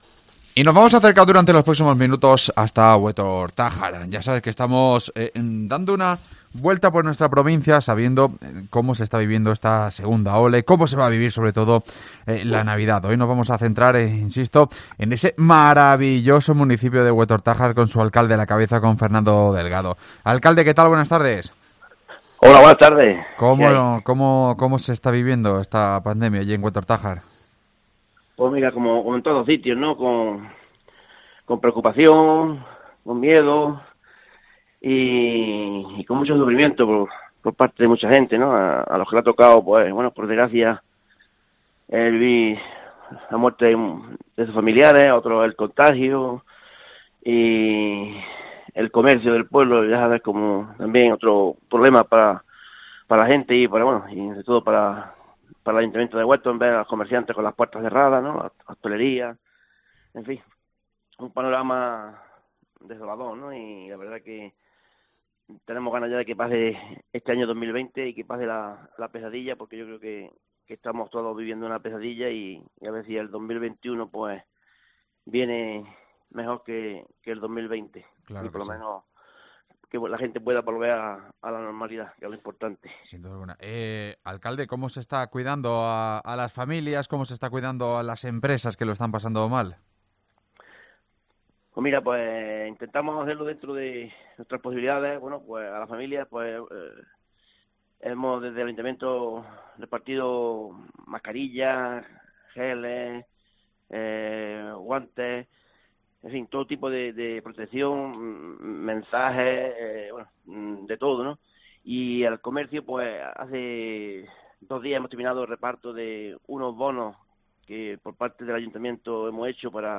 AUDIO: Hablamos con Fernando Delgado, alcalde del municipio del poniente granadino